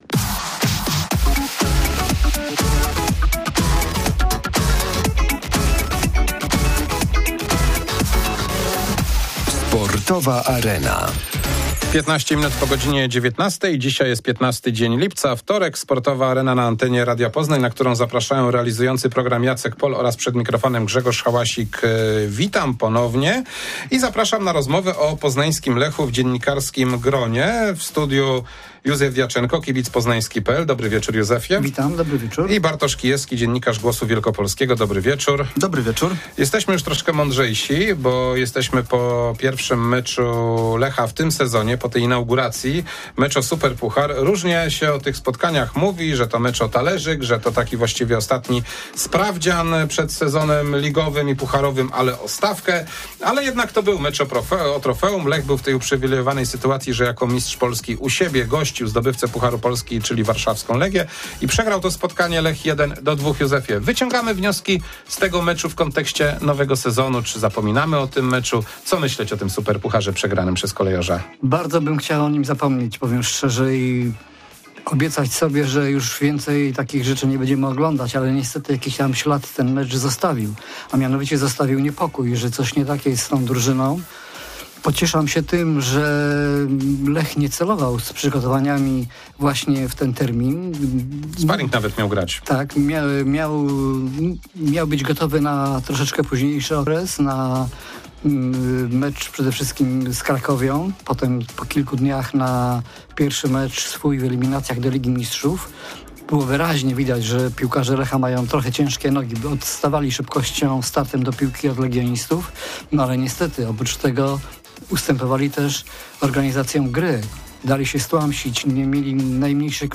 W Sportowej Arenie 15 lipca rozmowa o Lechu Poznań z dziennikarzami